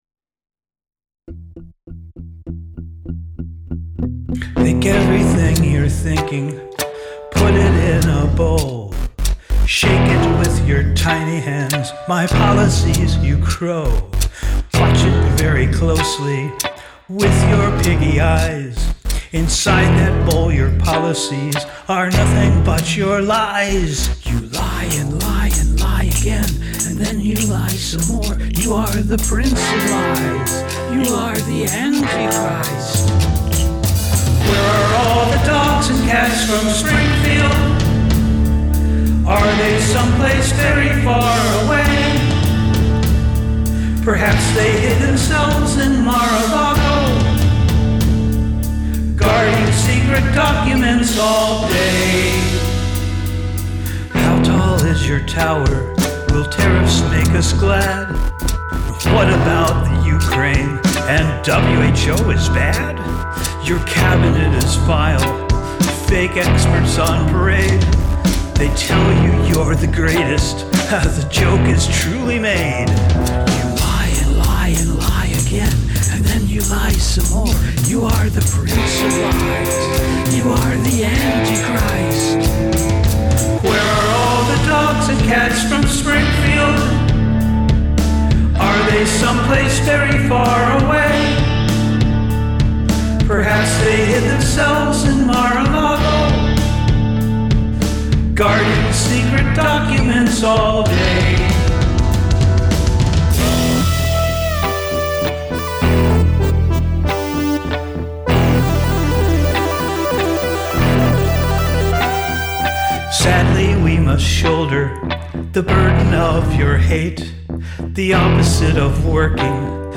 So I got out my guitar and dusted it off and recorded springfield. Pretending to be a rock star once again!
It certainly doesn't break any major musical ground: straightahead verse/chorus stuff (but a fun little synth solo!).